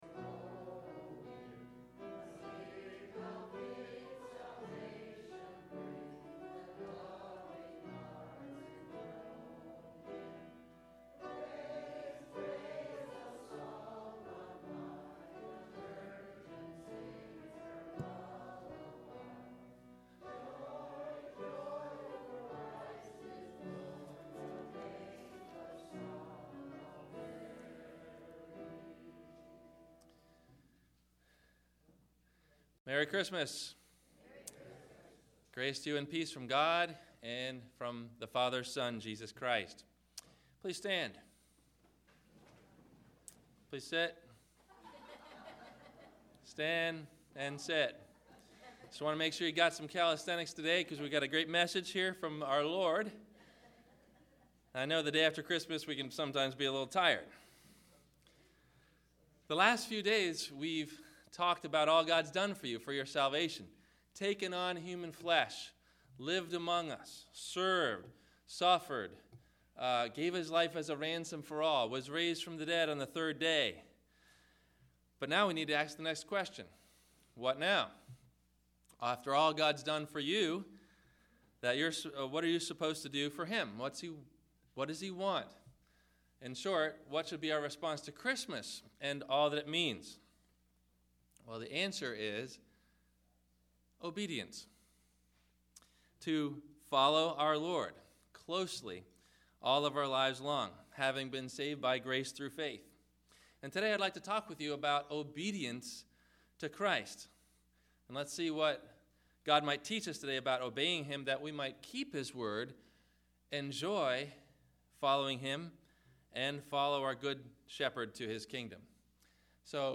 What Now ? Christian Obedience – Sermon – December 26 2010